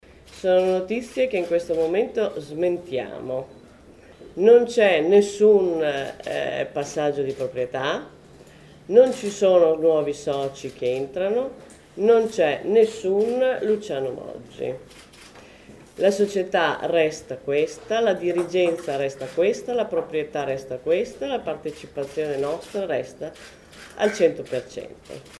30 giu. – 11.30, Casteledebole. La presidente del Bologna FC ha appena smentito l’ipotesi di un ingresso in società dell’ex dg della Juventus con una cordata che avrebbe rilevato il 40%.
Oggi, alle 11, la società ha indetto una conferenza stampa al centro sportivo di Casteldebole.